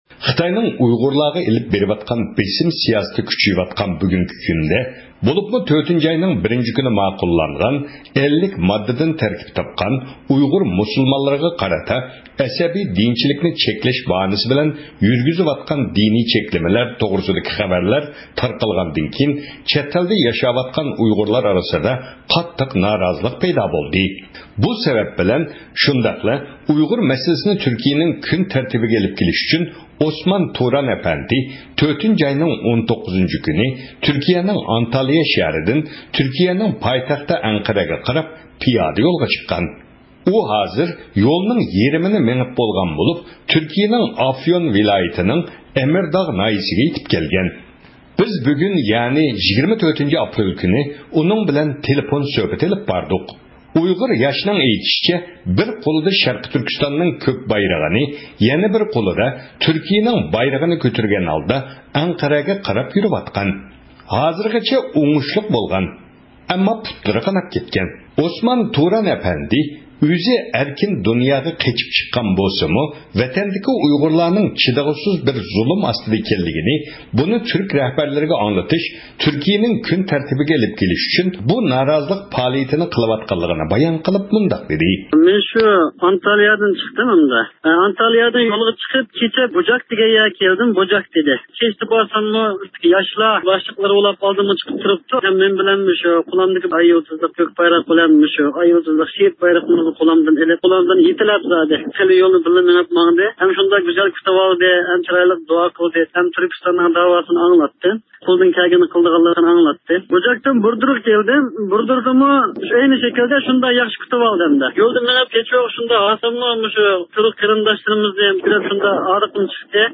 بىز بۈگۈن يەنى 24-ئاپرېل كۈنى ئۇنىڭ بىلەن تېلېفون سۆھبىتى ئېلىپ باردۇق.